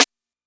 SNARE I.wav